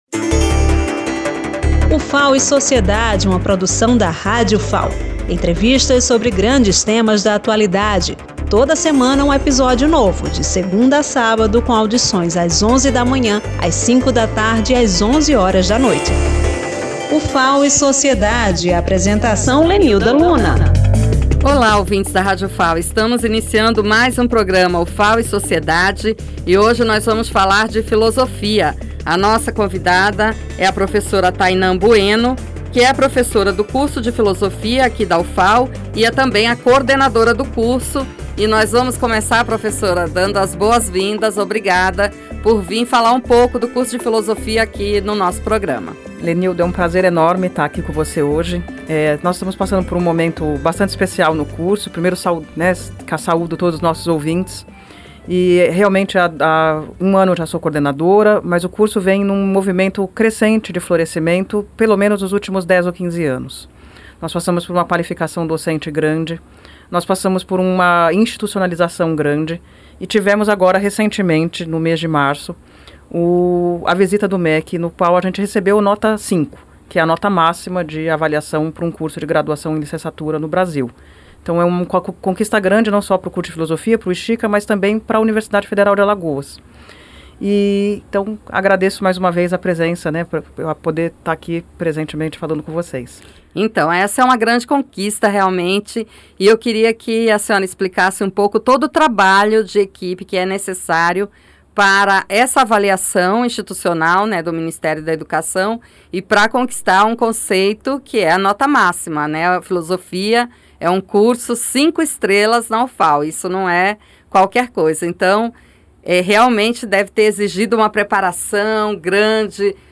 Outro destaque da entrevista será o projeto "Civitas", que busca levar reflexões filosóficas e políticas para a sociedade, promovendo debates sobre cidadania, direitos humanos e humanização. A iniciativa ocorre dentro e fora da universidade, alcançando comunidades e escolas, estimulando o pensamento crítico sobre temas como democracia, exclusão, tolerância e soberania.